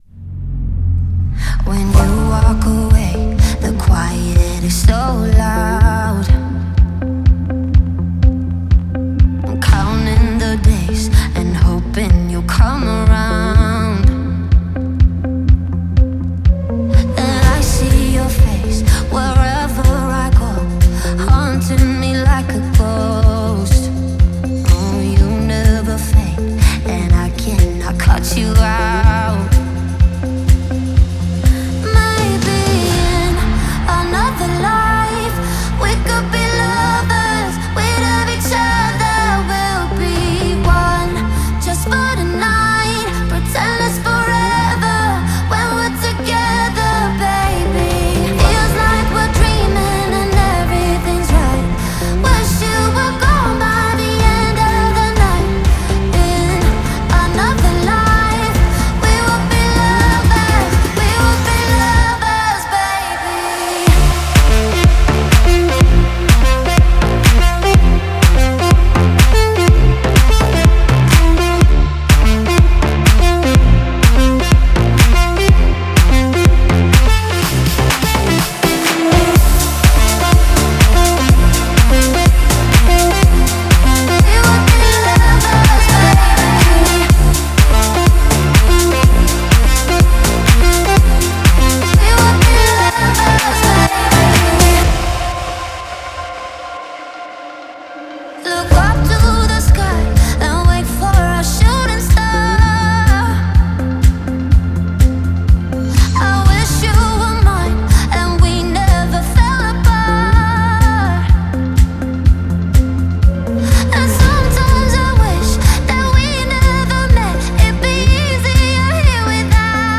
а динамичные биты создают атмосферу праздника.